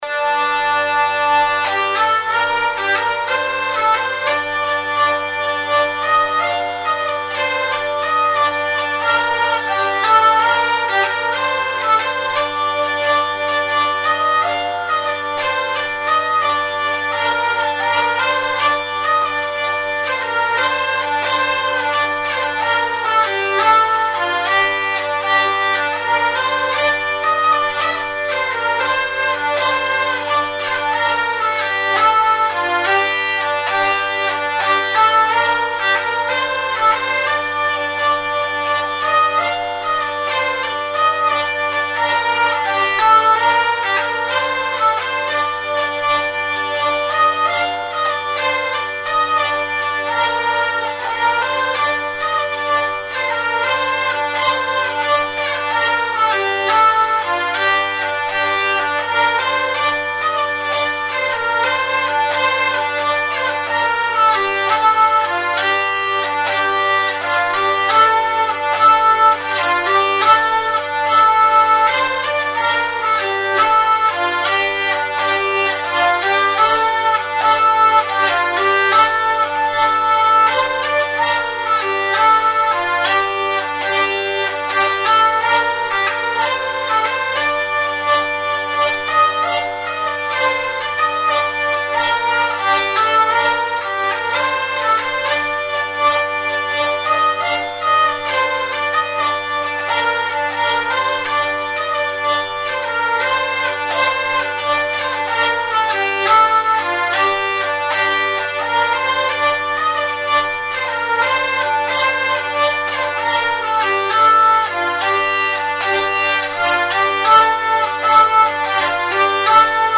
Hurdy Gurdy